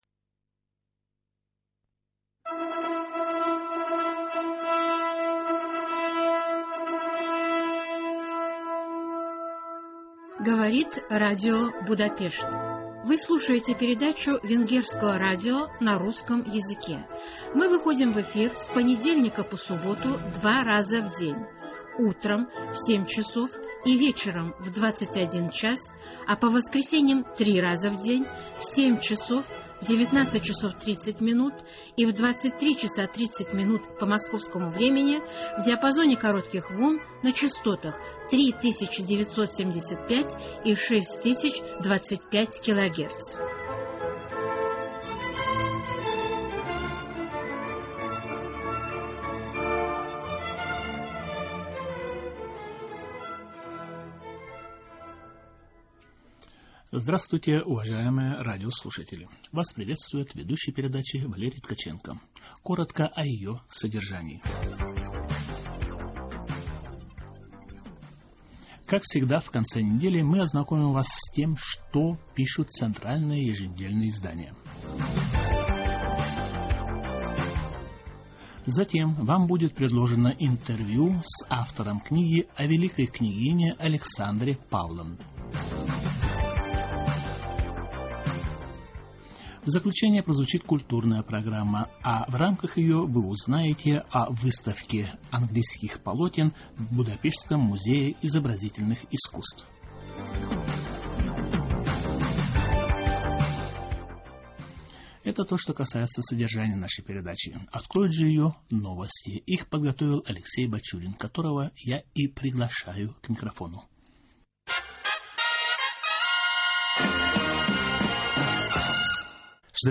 Интервью с автором книги...